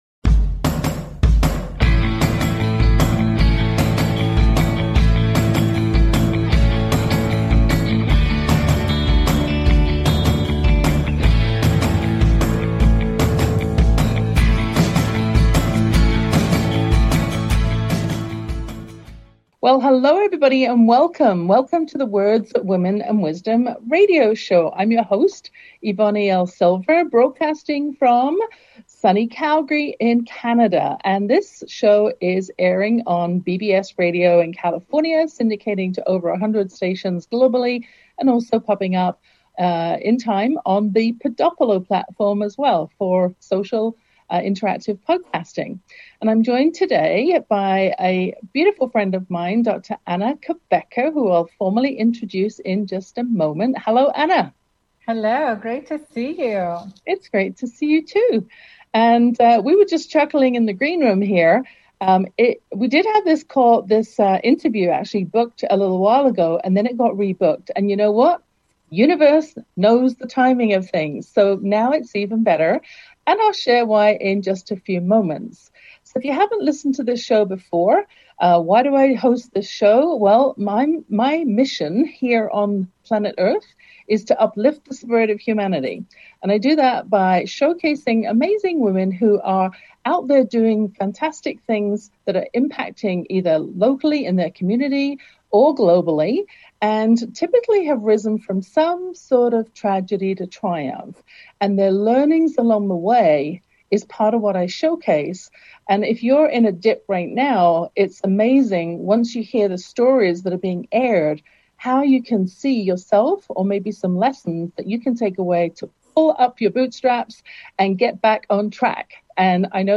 Talk Show Episode, Audio Podcast, Words Women and Wisdom Show and Guest